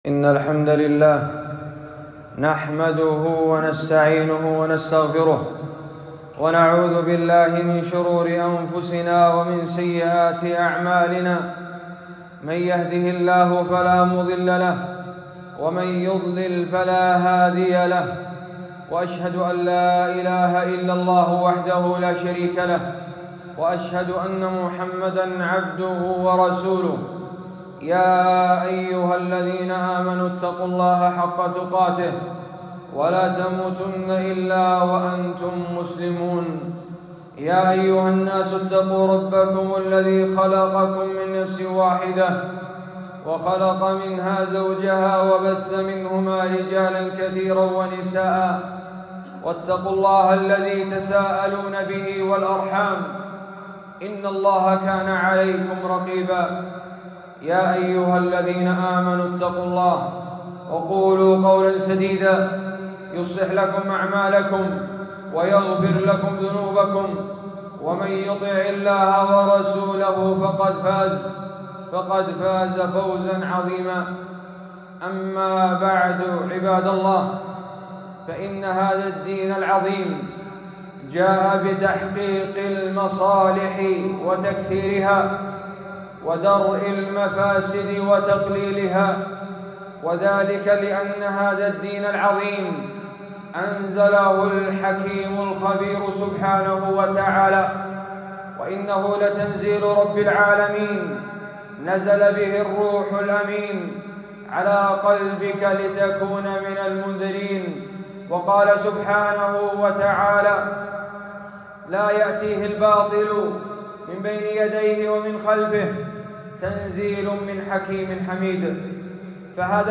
خطبة - حفظ الضروريات في الكتاب والسنة